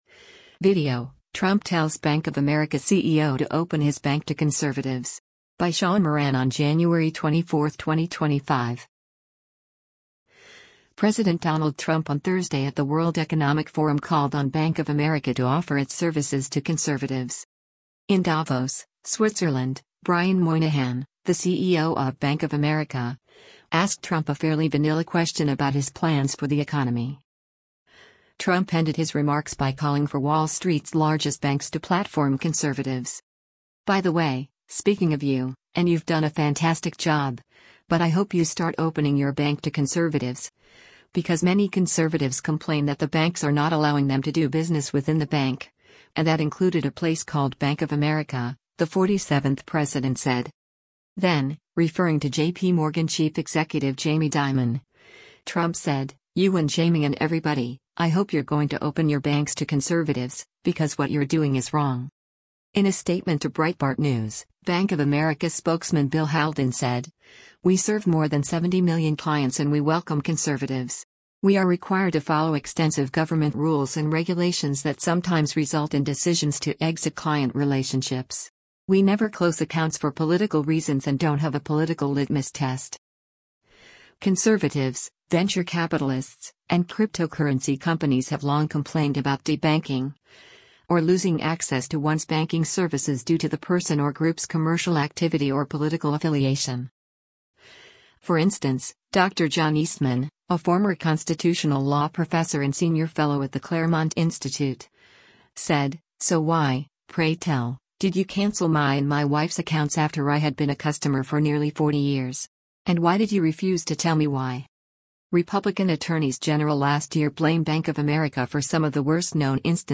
President Donald Trump on Thursday at the World Economic Forum called on Bank of America to offer its services to conservatives.
In Davos, Switzerland, Brian Moynihan, the CEO of Bank of America, asked Trump a fairly vanilla question about his plans for the economy.